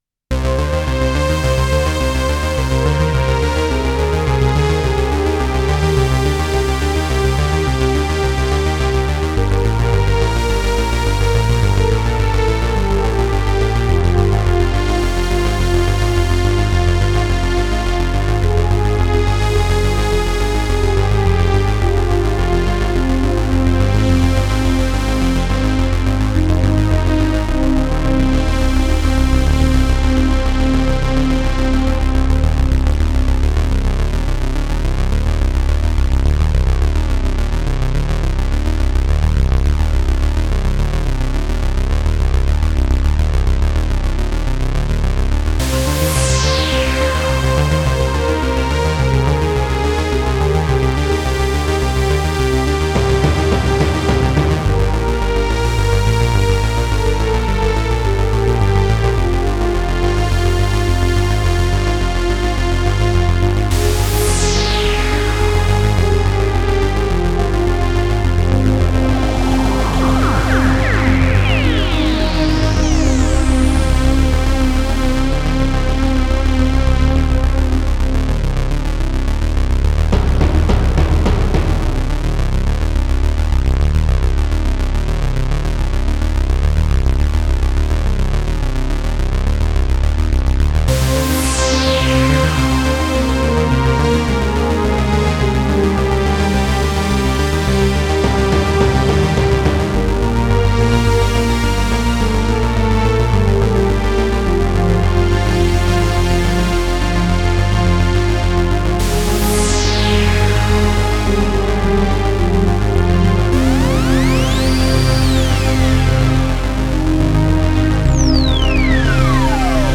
Demo Pack including 8 patches, 2 sets , and 2 drum kits.
Retro Futurism is a kaleidoscope of 80s nostalgia and sci-fi future, a perfect crossover between Stranger Things and Blade Runner.
This pack takes inspiration from some of the most iconic synthesizers of the past, blending their sound with a more futuristic aesthetic.